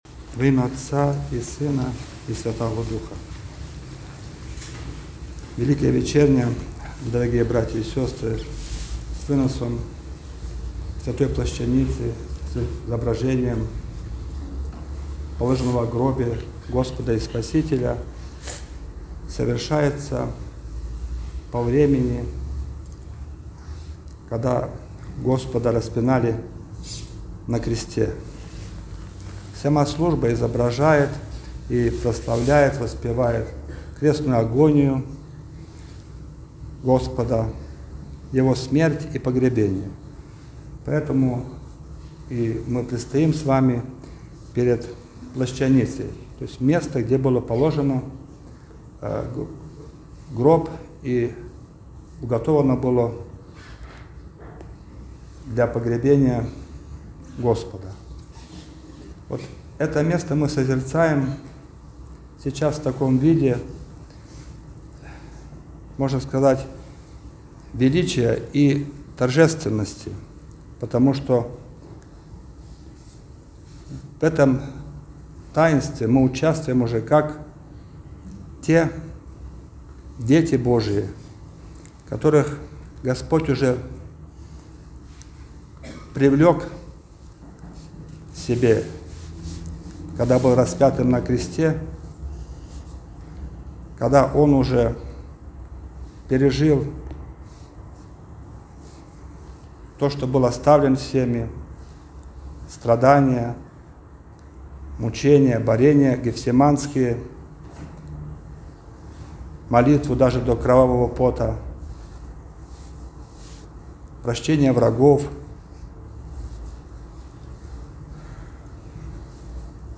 Проповедь
Вынос-Плащаницы.mp3